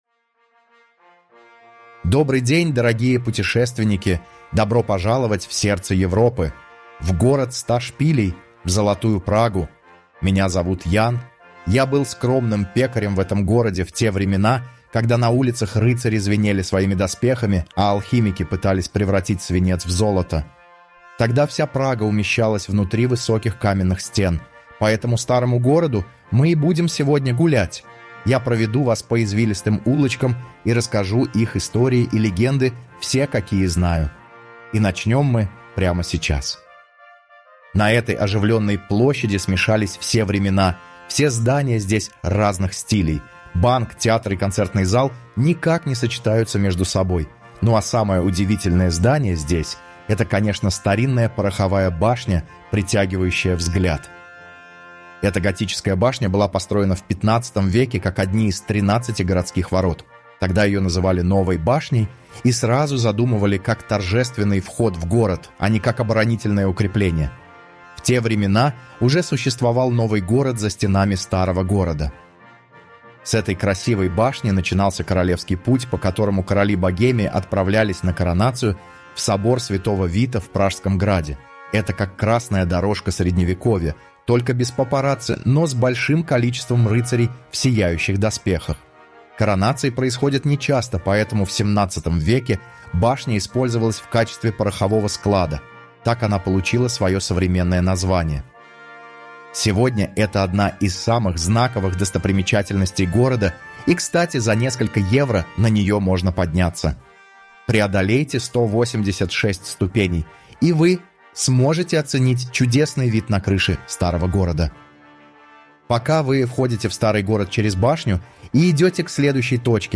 Удобный маршрут проведет вас по самым интересным местам Прагми, а голос в аудиогиде поможет увидеть город по-новому.